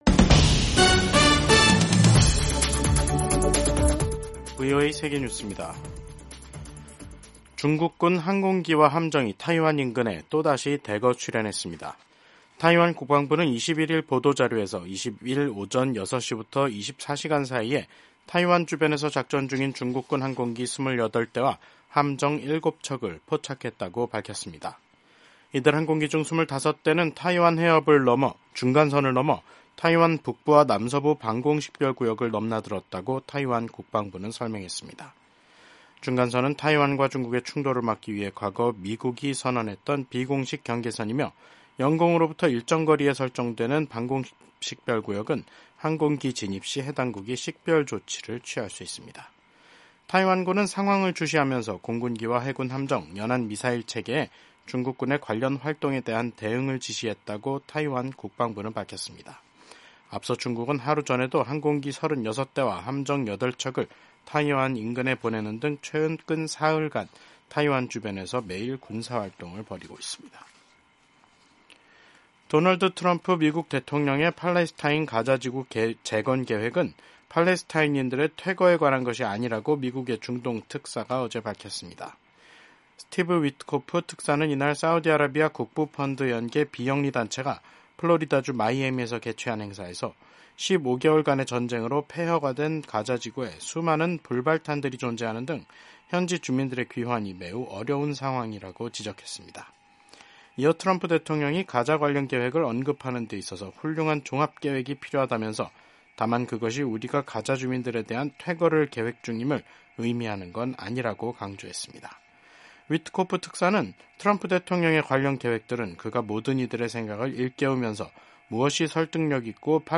세계 뉴스와 함께 미국의 모든 것을 소개하는 '생방송 여기는 워싱턴입니다', 2025년 2월 21일 저녁 방송입니다.